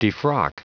Prononciation du mot defrock en anglais (fichier audio)
Prononciation du mot : defrock